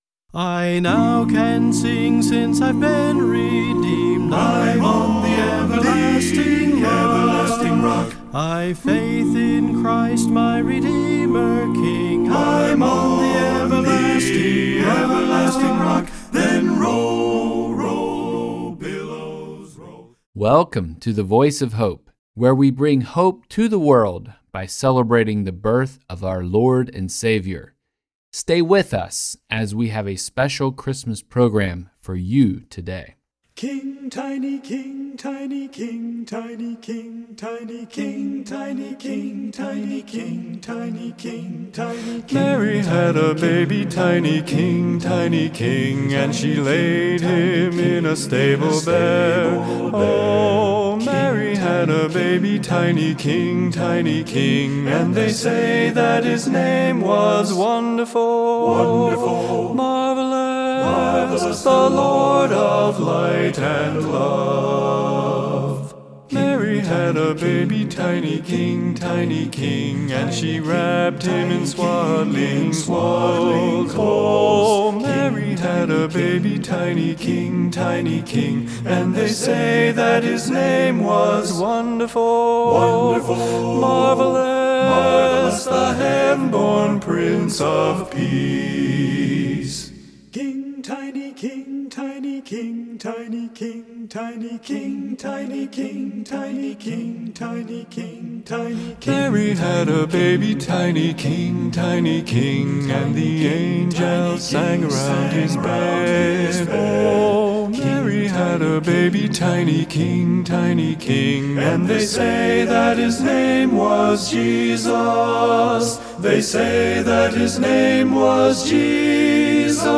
In this episode, we celebrate the birth of the Savior with a variety of musical selections that focus on different aspects of His coming to earth as a baby.